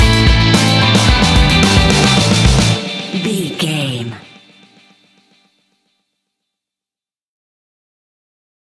Ionian/Major
Fast
energetic
driving
aggressive
electric guitar
bass guitar
drums
hard rock
heavy metal
heavy drums
distorted guitars
hammond organ